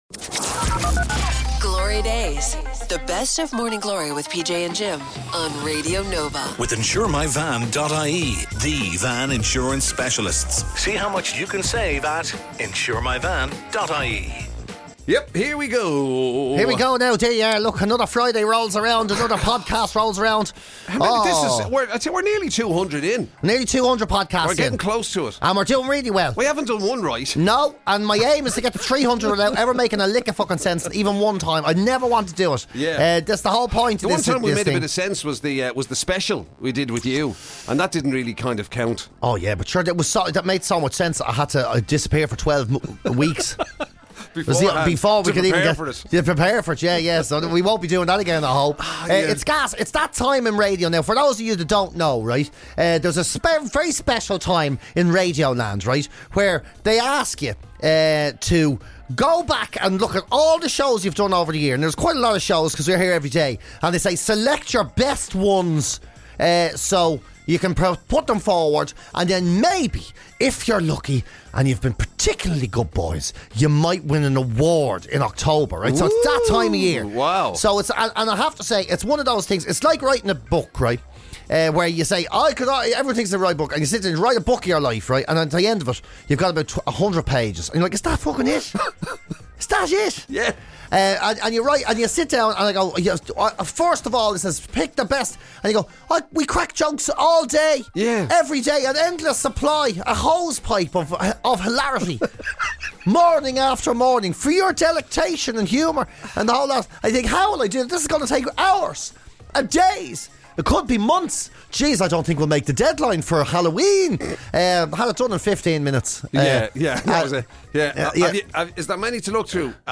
Warning this series (unlike the radio show!) contains explicit language that may be offensive to some listeners.
… continue reading 209 حلقات # Comedy # Radio Nova